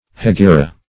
Hegira \He*gi"ra\ (?; 277), n. [Written also hejira.]